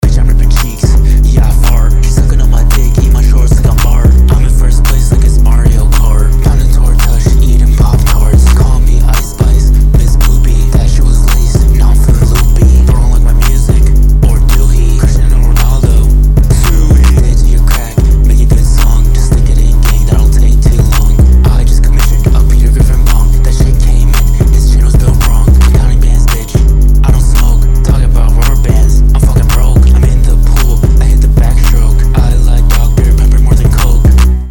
fart freestyle. this started off as a joke with a friend, before i decided to make it into a shitty, babytron-inspired mess. it's funny, though, so points for that.